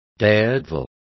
Complete with pronunciation of the translation of daredevil.